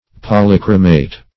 Polychromate \Pol`y*chro"mate\, n. [See Polychromatic.]